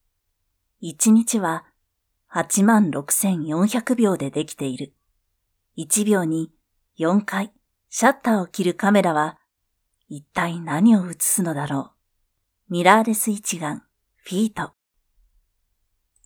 V O I C E